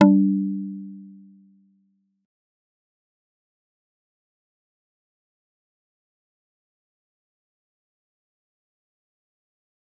G_Kalimba-G3-mf.wav